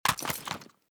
mp5_draw.ogg.bak